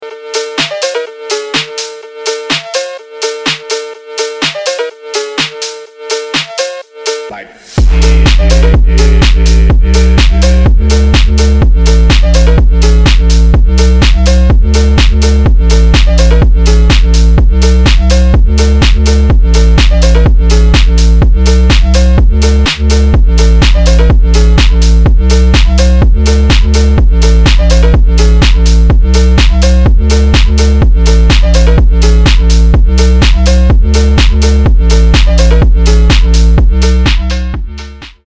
качающие
phonk
Офигенный фонк